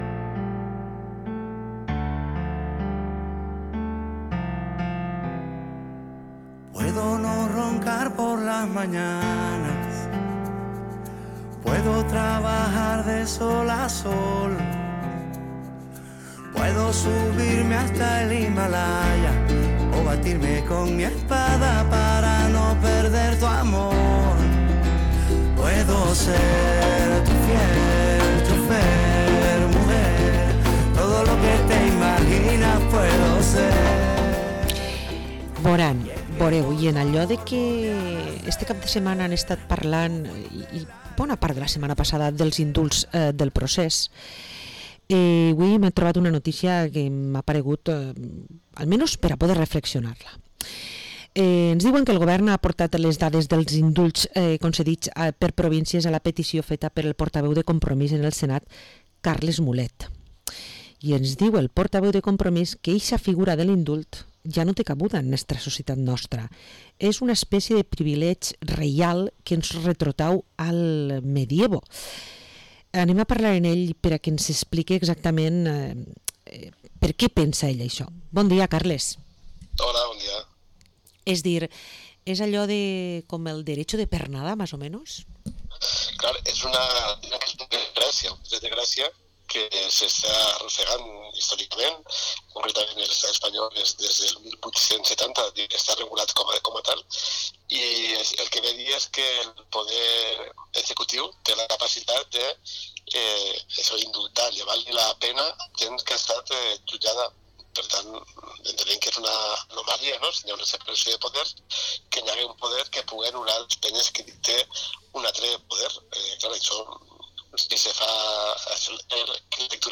Entrevista al senador de Compromís, Carles Mulet